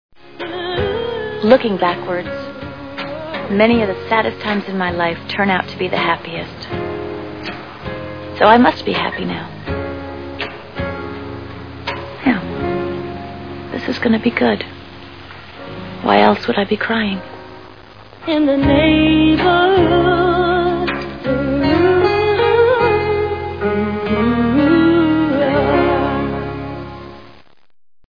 Ally McBeal TV Show Sound Bites